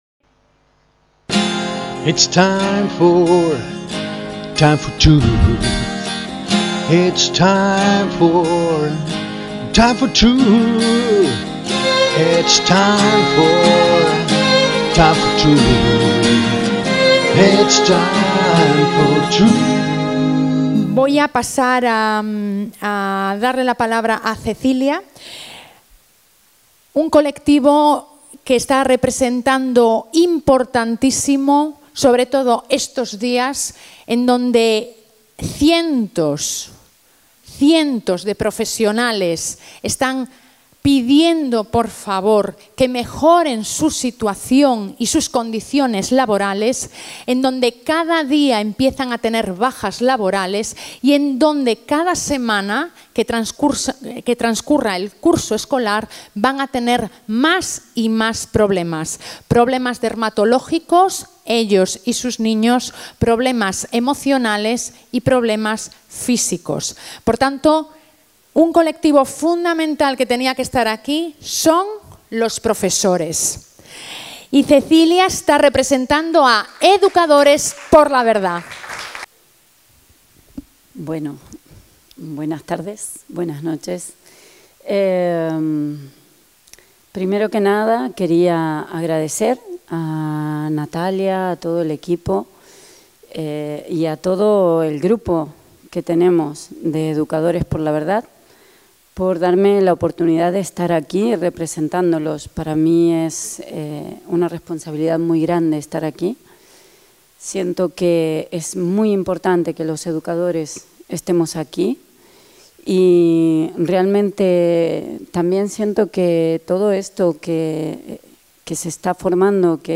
EDUCADORES POR LA VERDAD. Rueda de prensa de UNIDOS por la VERDAD y la VIDA EN LIBERTAD